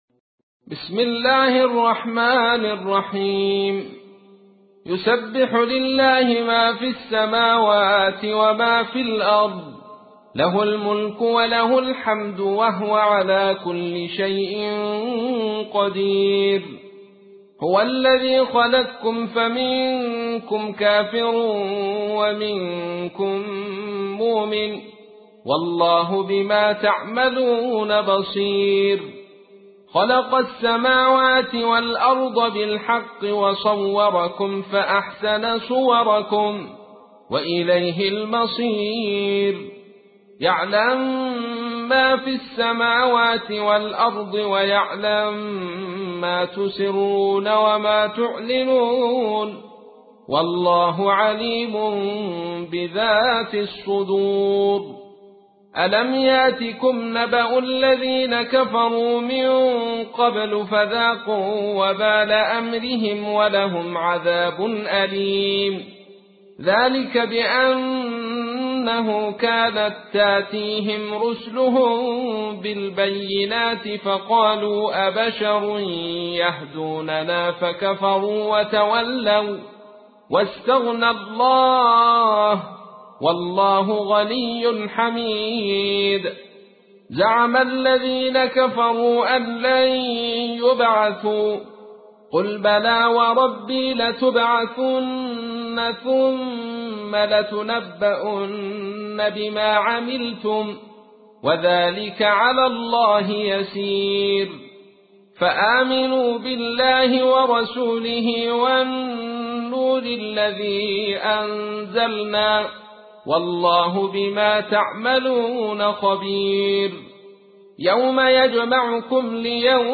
تحميل : 64. سورة التغابن / القارئ عبد الرشيد صوفي / القرآن الكريم / موقع يا حسين